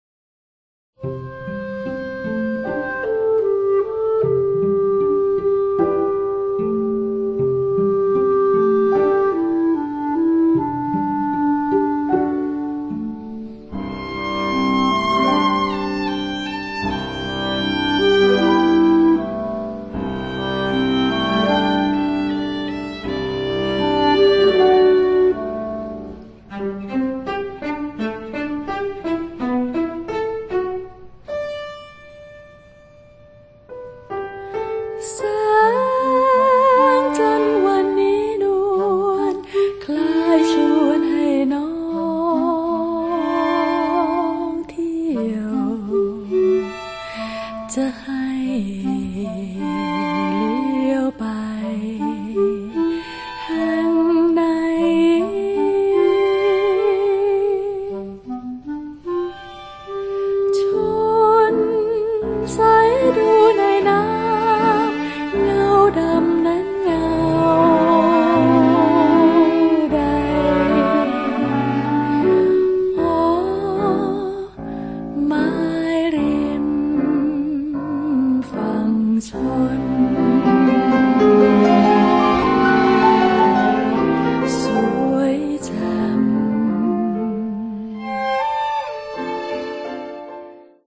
แผ่น UHQCD คุณภาพเสียงชั้นเลิศ ผลิตในประเทศญี่ปุ่น